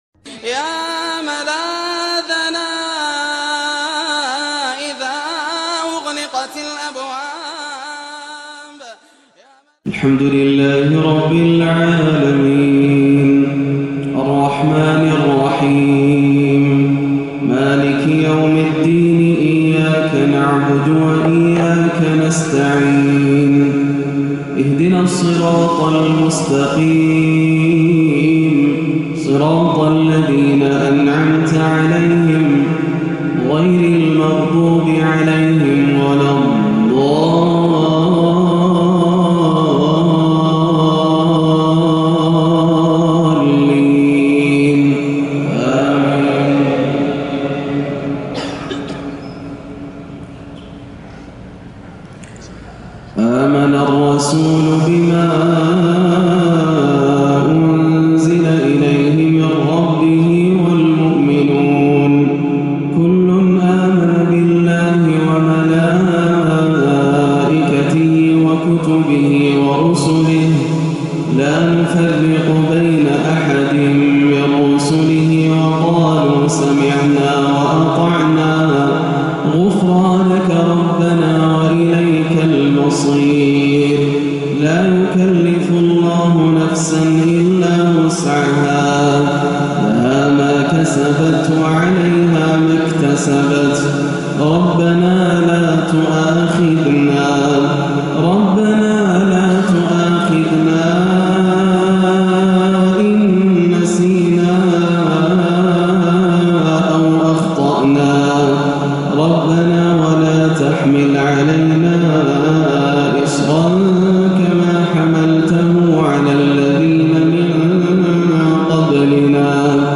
مغرب الأربعاء 2-2-1438هـ من سورتي البقرة 285-286 و آل عمران 8-9 > عام 1438 > الفروض - تلاوات ياسر الدوسري